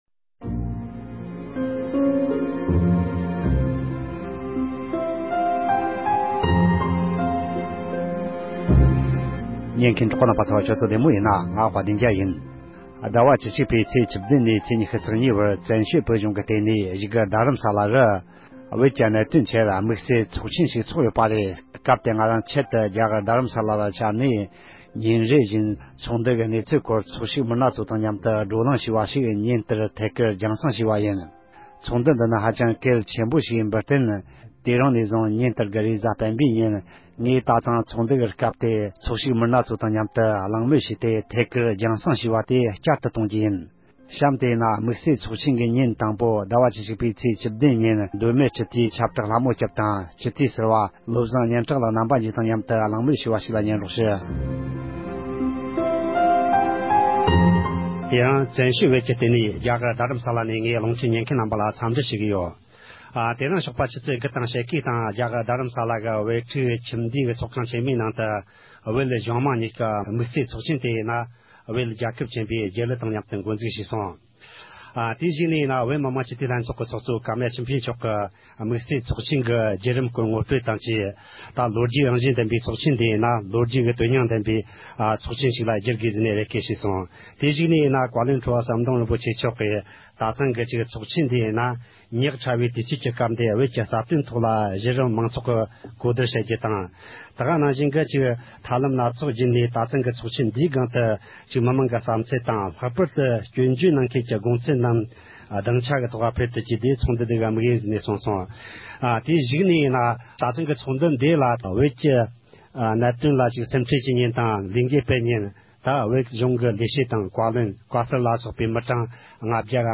མདོ་སྨད་སྤྱི་འཐུས་ཆབ་བག་ལྷ་མོ་སྐྱབས་དང་སྤྱི་འཐུས་ཟུར་པ་བློ་བཟང་སྙན་གྲགས་ལཌ་རྣམ་པ་གཉིས་ཀྱིས་ཚོཌ་ཆེན་སྐོར་འགྲེལ་བརྗོད་གནང་བ།
གྱིས་ཚོགས་ཆེན་གྱི་སྐོར་བཀའ་འདྲི་ཞུས་པ་ཞིག་གསན་རོགས་གནང་༎